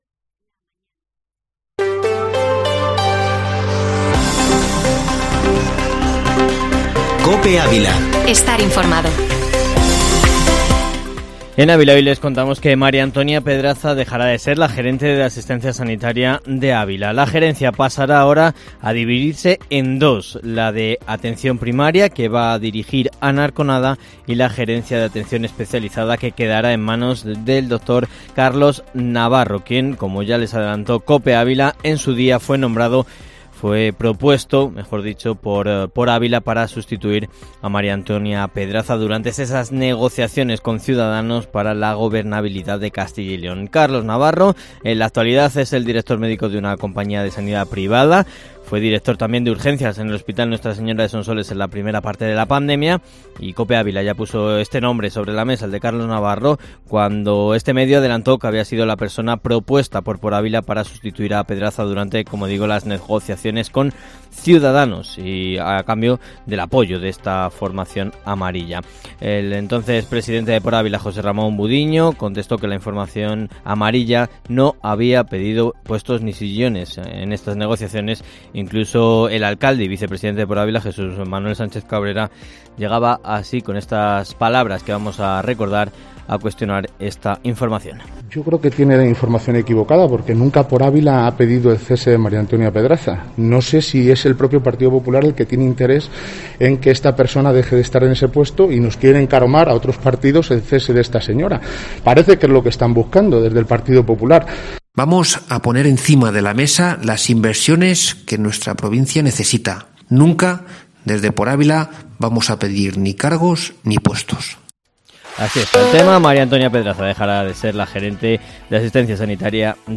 Informativo Matinal Herrera en COPE Ávila -14-oct